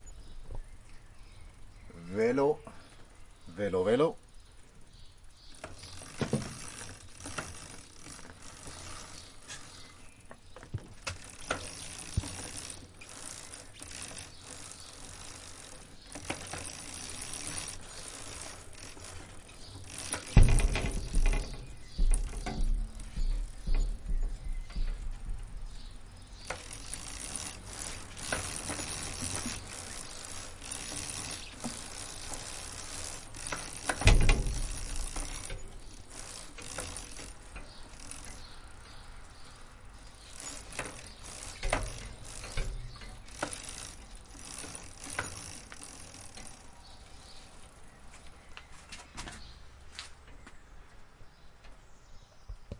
自行车骑行的声音 " 自行车骑行的声音 1
Tag: 骑车为 蹬踏 链条 自行车 车轮